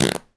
pgs/Assets/Audio/Comedy_Cartoon/fart_squirt_02.wav
fart_squirt_02.wav